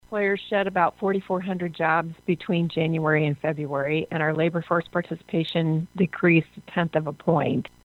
WORKFORCE DEVELOPMENT DIRECTOR BETH TOWNSEND SAYS THERE ARE SOME SIGNS OF TIGHTENING IN SECTIONS OF THE ECONOMY.